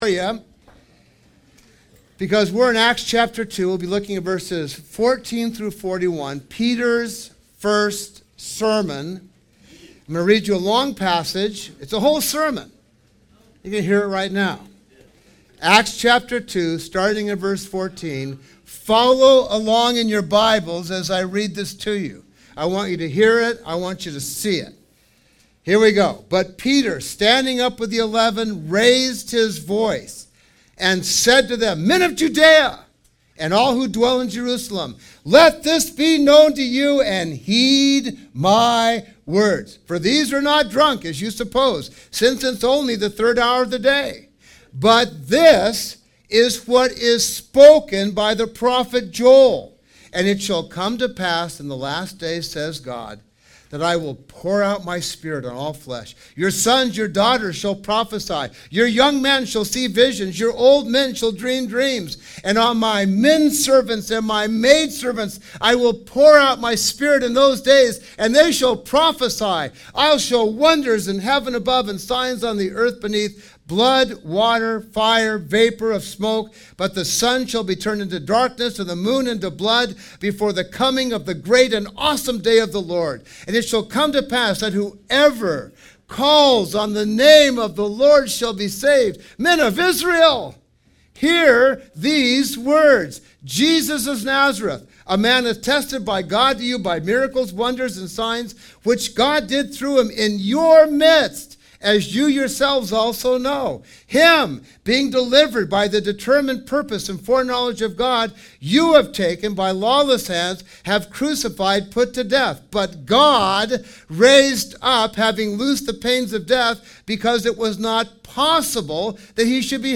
Peter's First Sermon - Harvest Kumulani Maui